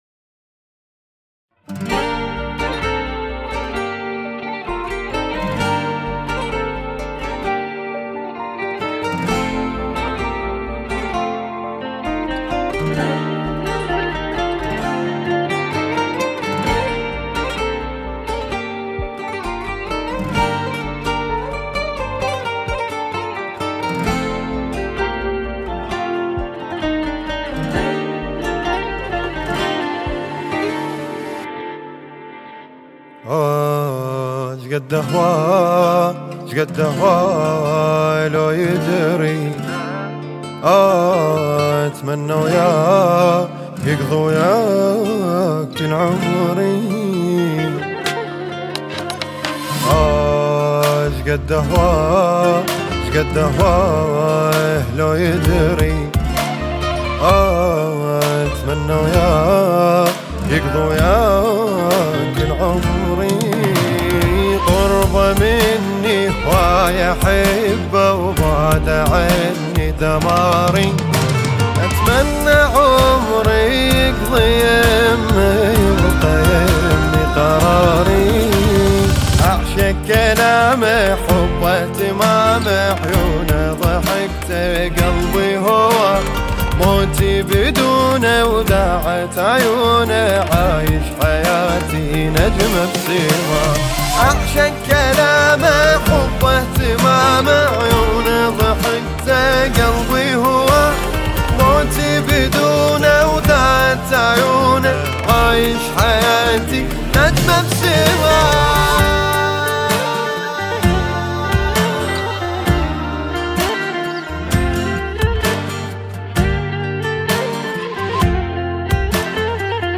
بلحنها العاطفي